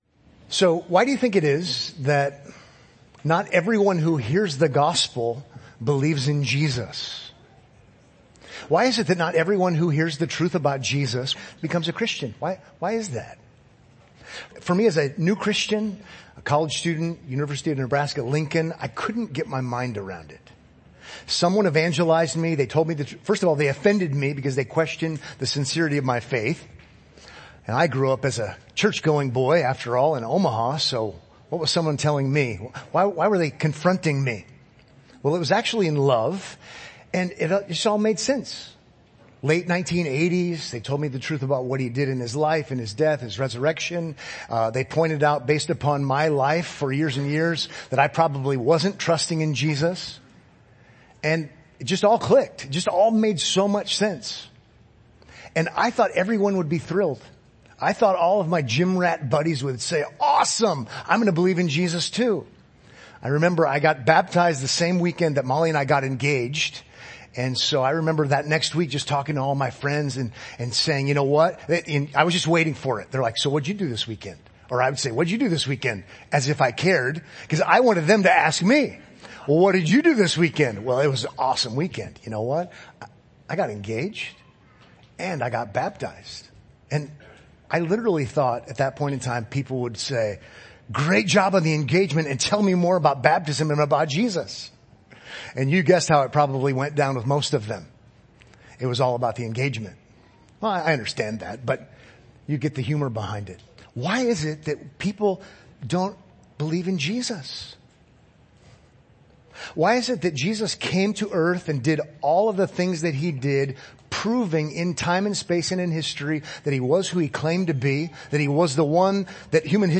Sermons Podcast - Messianic Mysteries | Free Listening on Podbean App